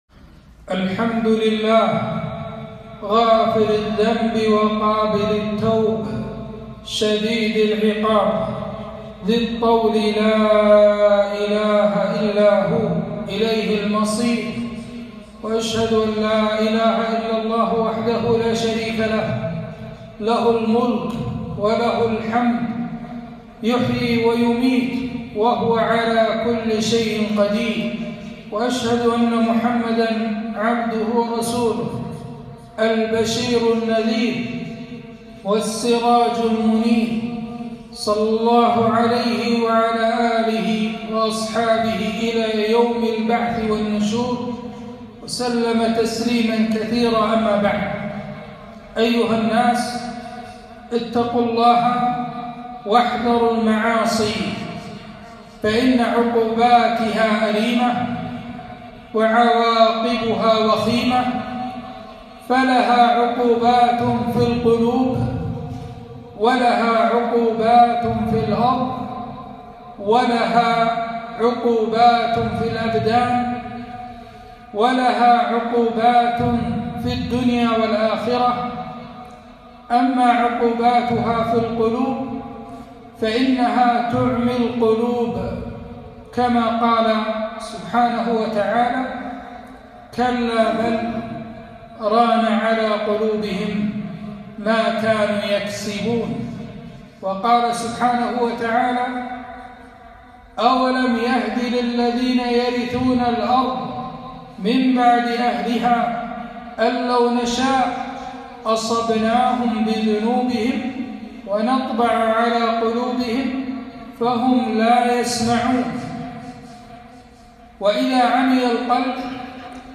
خطبة - عواقب الذنوب والمعاصي على الأمم